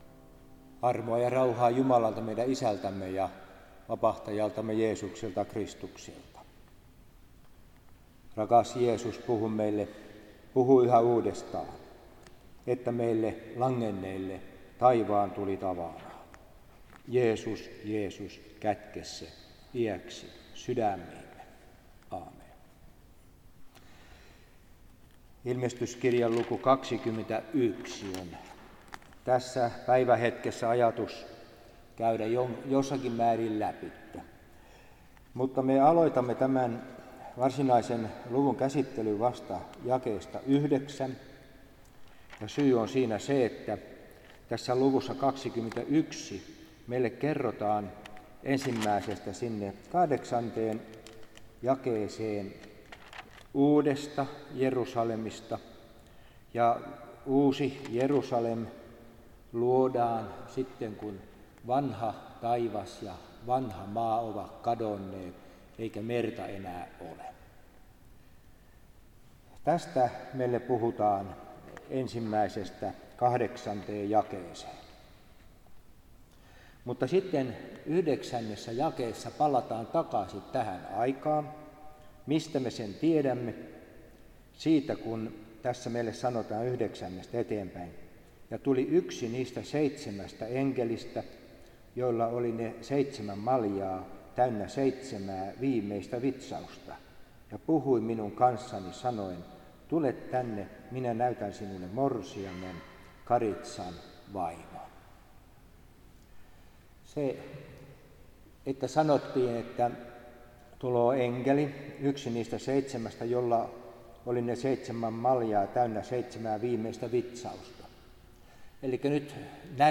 Ilm.kirjan luento; luku 21
Kristiinankaupunki